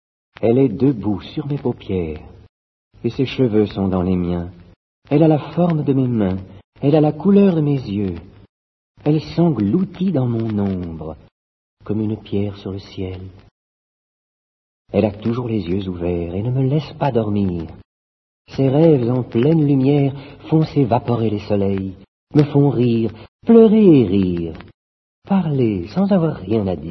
/wp-content/uploads/2011/01/amoureuse.mp3 dit par Gérard PHILIPPE Paul ÉLUARD ( Mourir ne pas mourir ,1924)